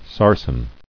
[sar·sen]